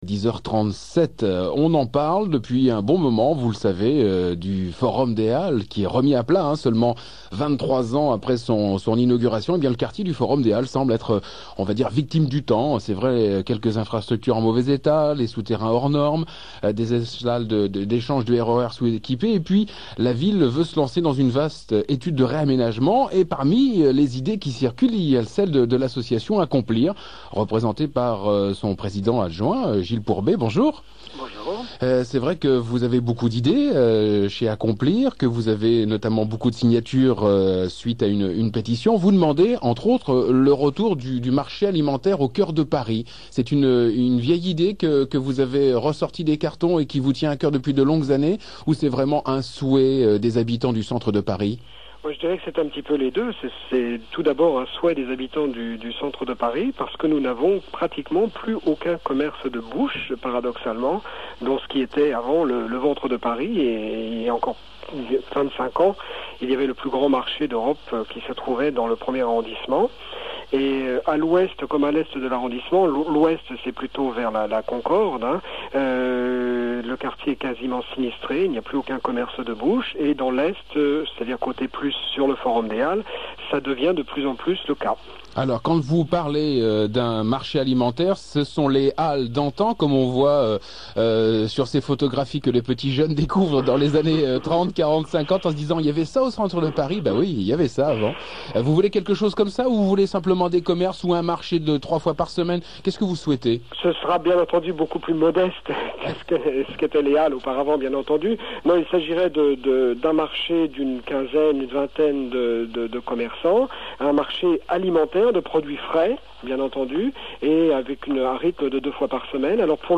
La fête dans le 1er arrondissement. En bonustrack et exclusivité mondiale: la version live de "Mon amant de Saint-Jean".